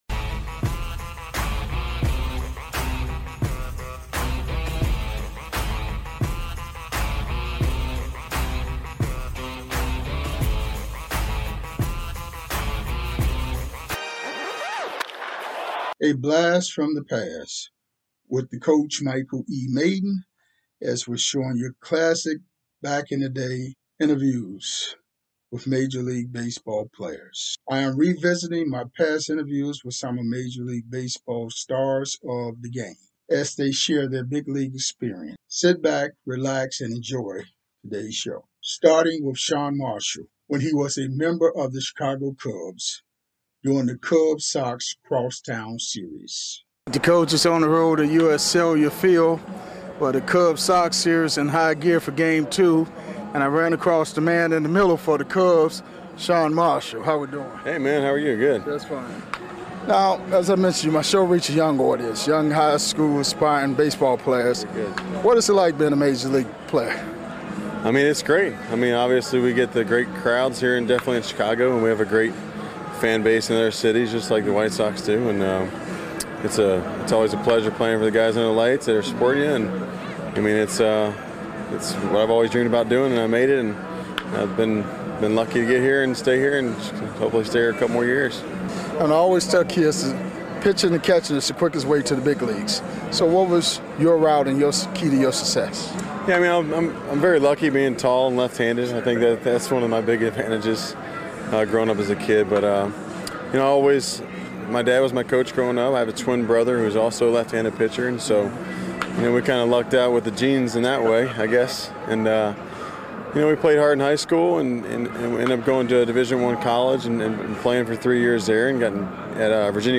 Classic Major League Baseball Interviews 104